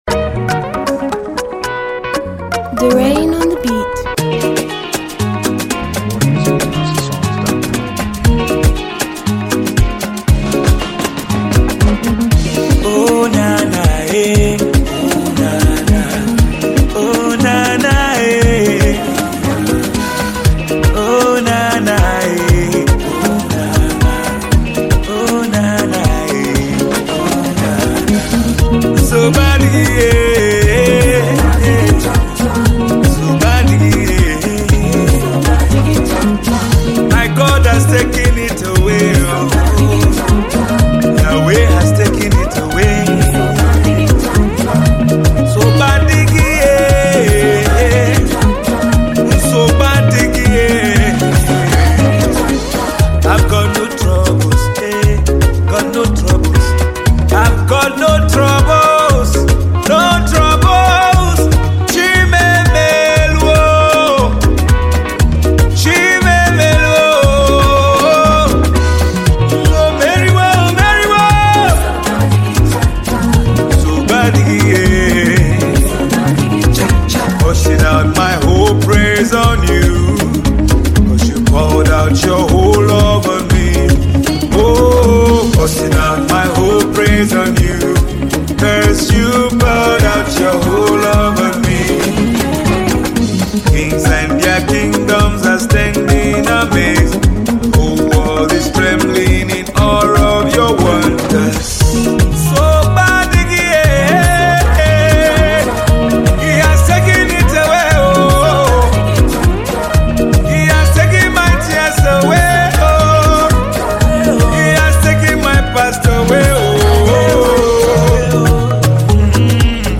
Tiv Song